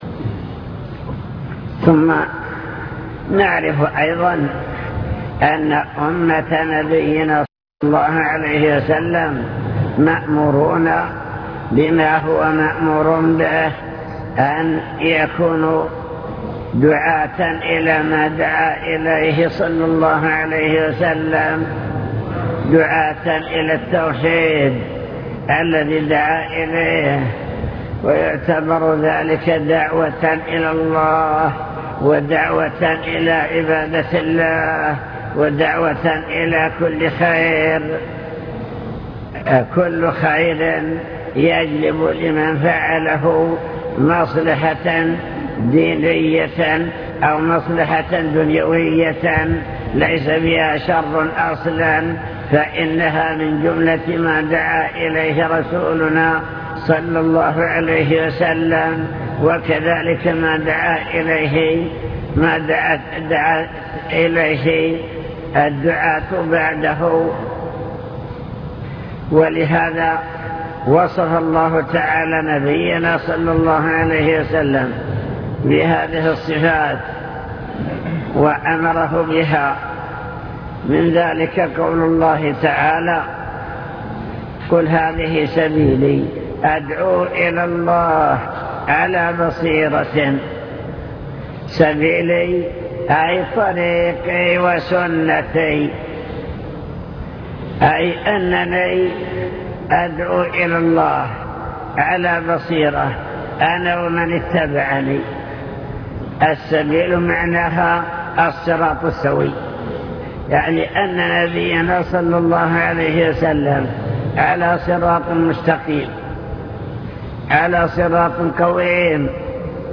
المكتبة الصوتية  تسجيلات - محاضرات ودروس  محاضرة في جامع حطين دعوة الأنبياء والرسل